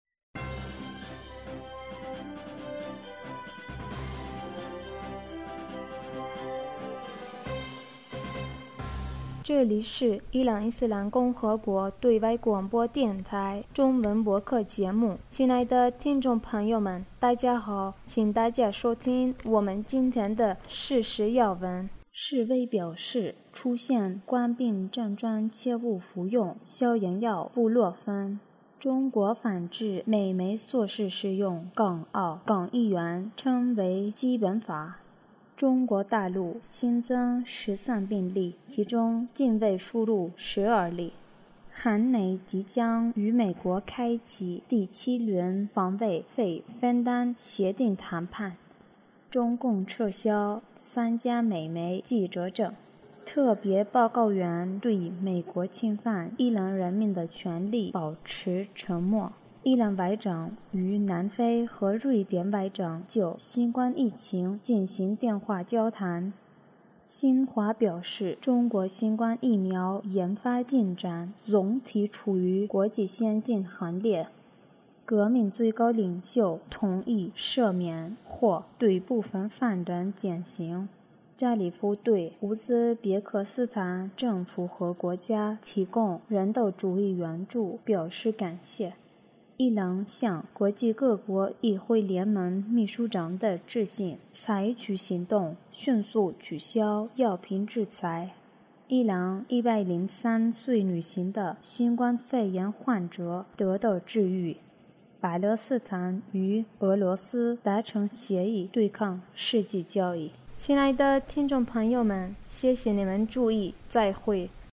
2020年 3月18日 新闻